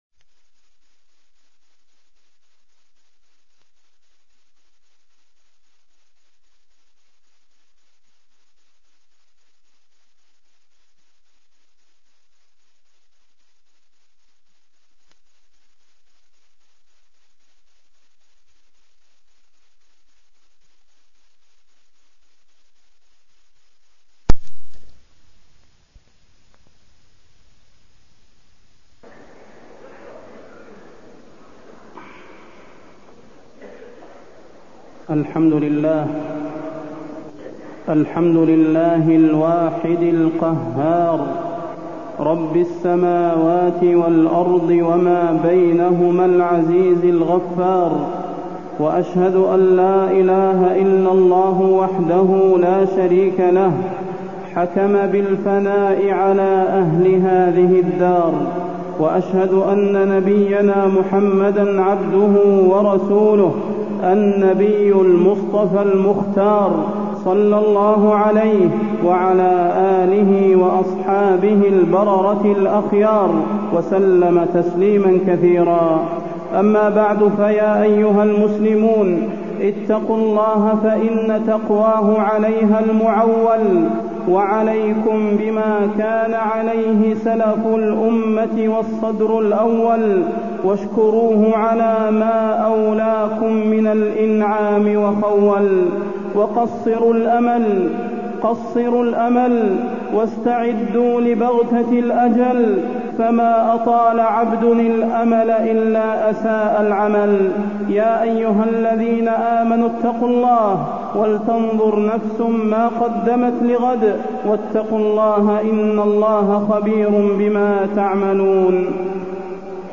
تاريخ النشر ١ محرم ١٤٢٣ هـ المكان: المسجد النبوي الشيخ: فضيلة الشيخ د. صلاح بن محمد البدير فضيلة الشيخ د. صلاح بن محمد البدير محاسبة النفس ونهاية العام